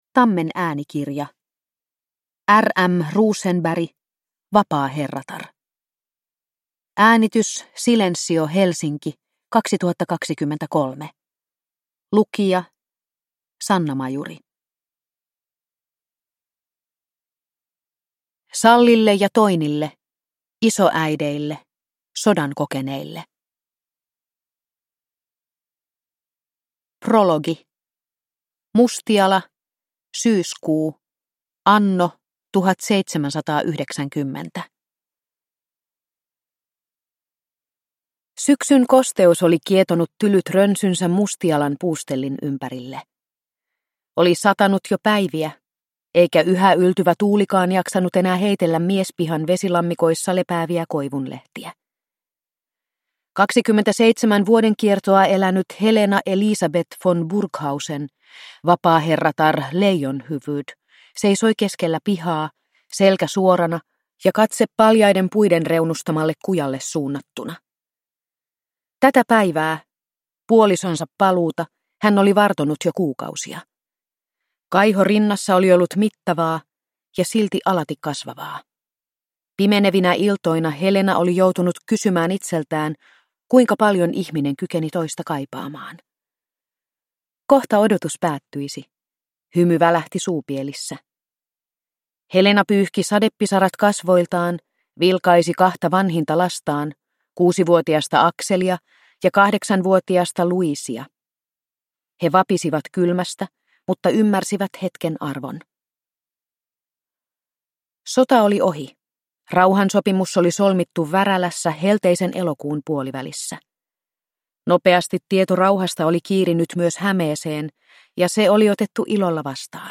Vapaaherratar (ljudbok) av R. M. Rosenberg